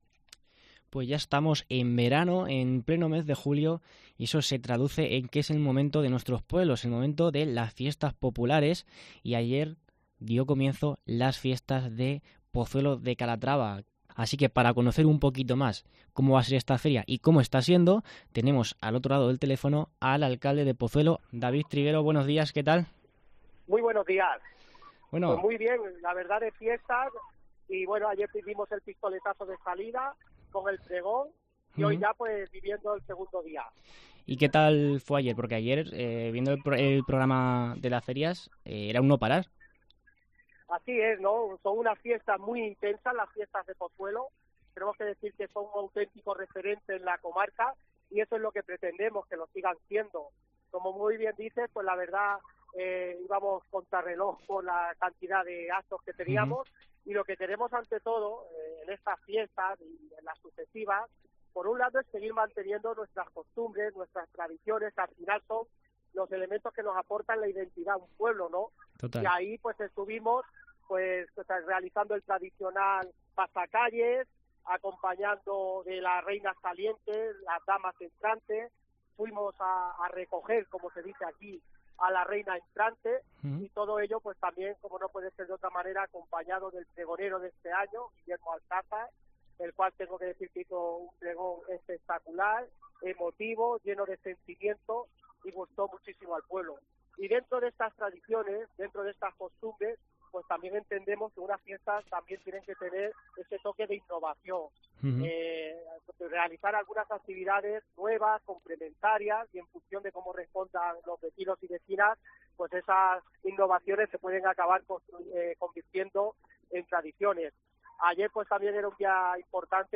Viernes 17 de julio: entrevista a David Triguero, alcalde de Pozuelo de Calatrava